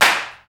87 BIG CLP-R.wav